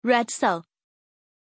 Pronunciation of Rezzo
pronunciation-rezzo-zh.mp3